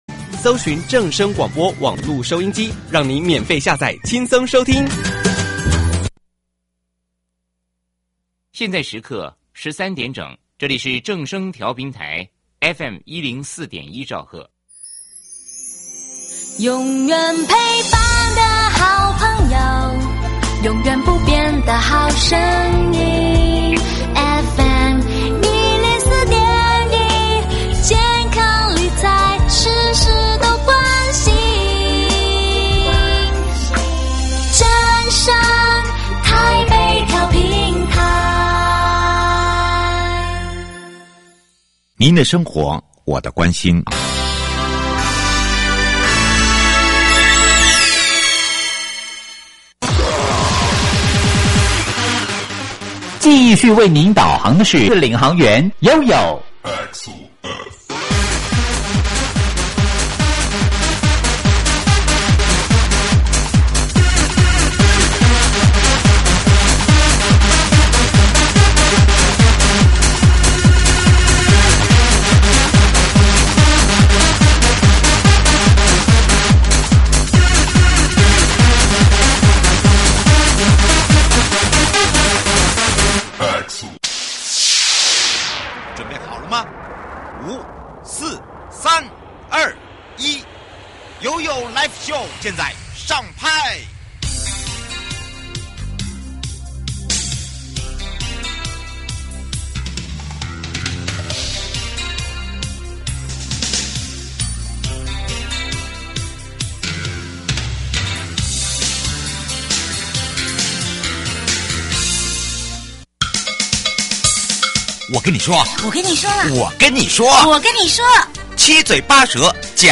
受訪者： 1.國土署 都市基礎工程組